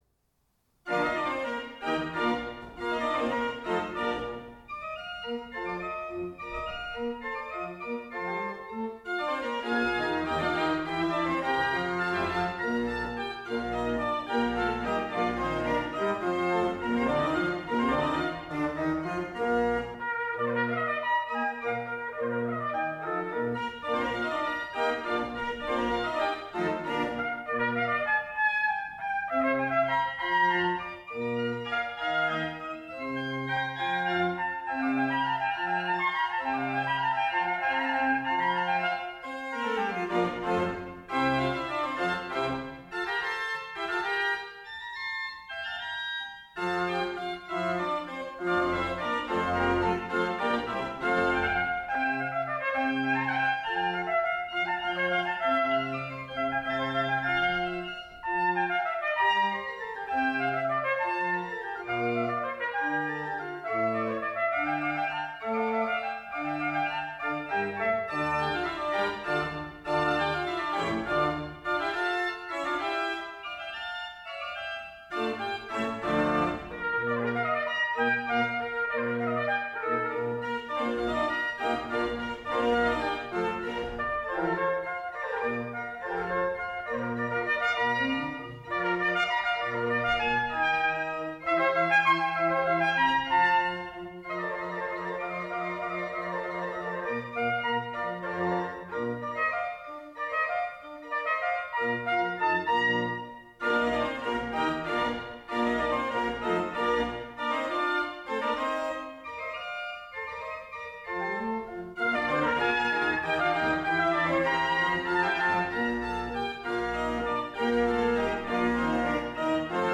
bearbeitet für Orgel und Trompete.